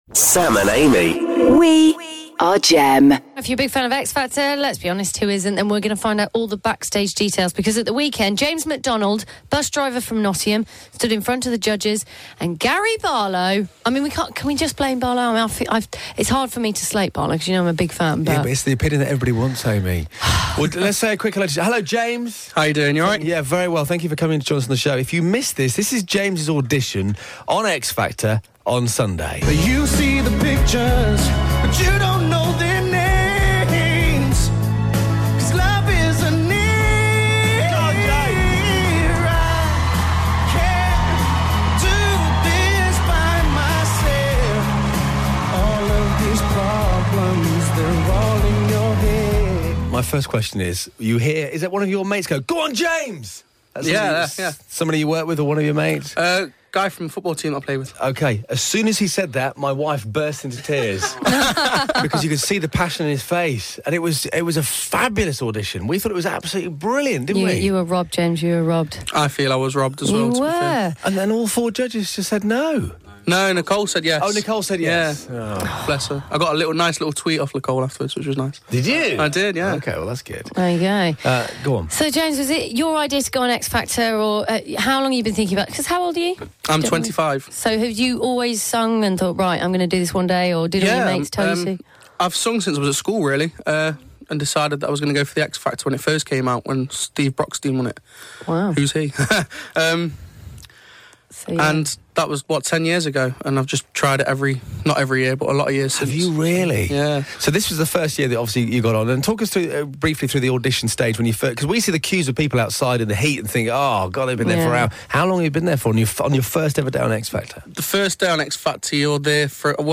joined us on the show this morning to give us all the backstage gossip.